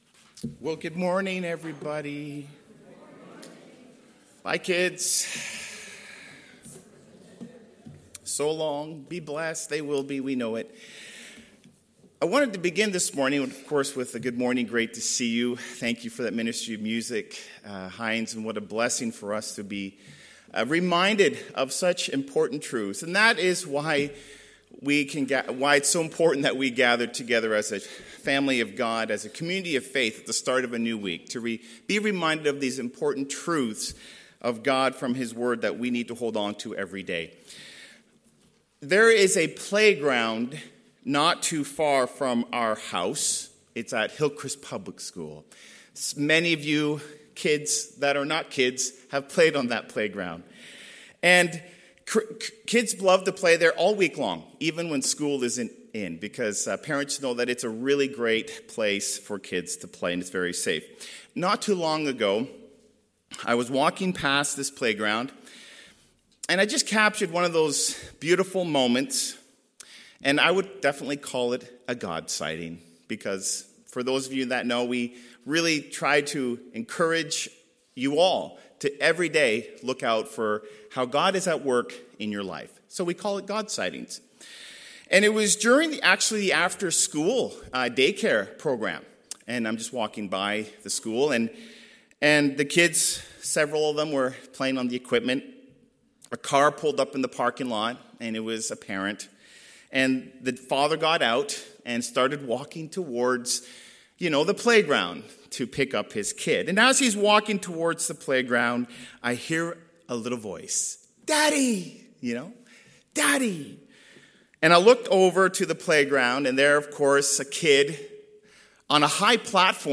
Audio Sermons - Campbellford Baptist Church Inc.